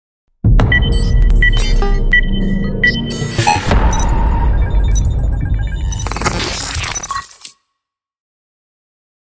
User Interface Digital Transition SFX